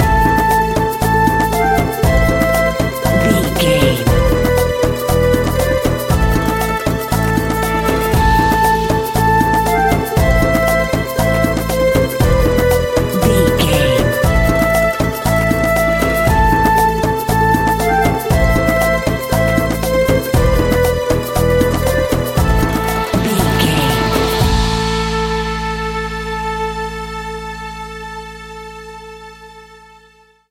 Aeolian/Minor
Indian
World Music
percussion